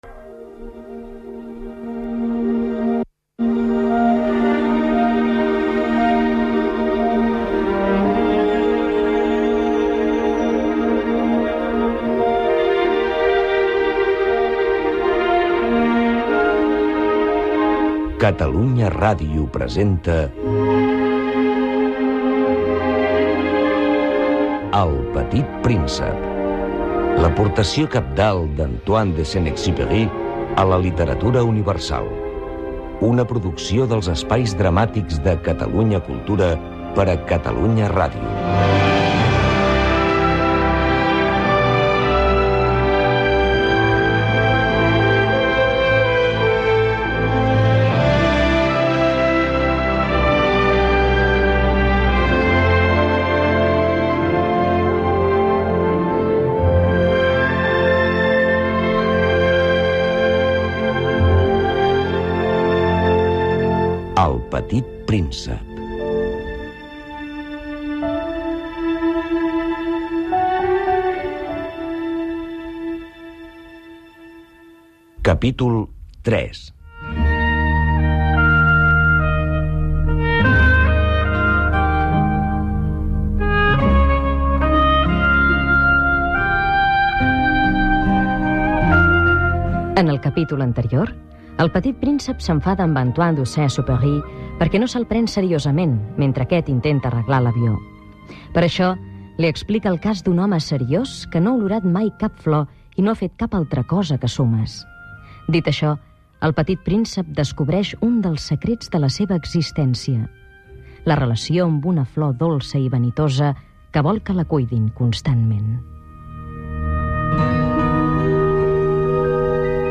Adaptació radiofònica de l'obra "El petit príncep" d'Antoine de Saint-Exupery. Careta del programa, inici del capítol 3 amb el resum de l'anterior i la relació del personatge amb una flor.
Ficció